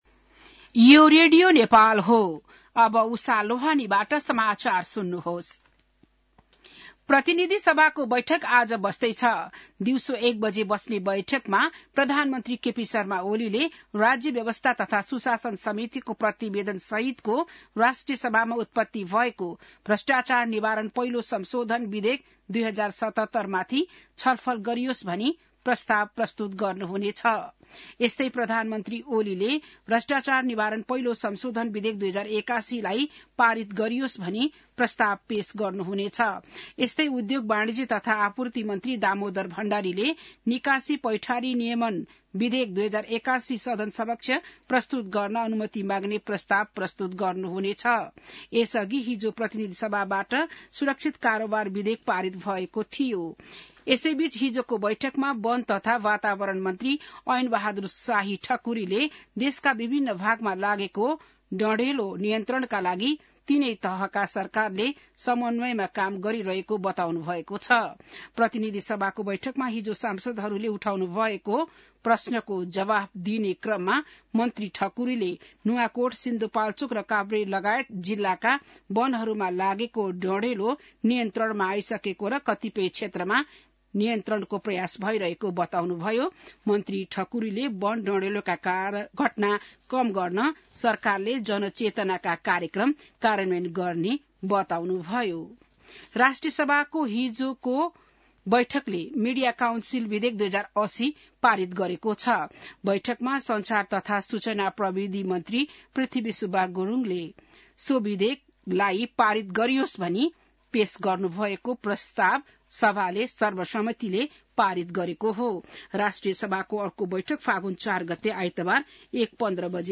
An online outlet of Nepal's national radio broadcaster
बिहान १० बजेको नेपाली समाचार : ३० माघ , २०८१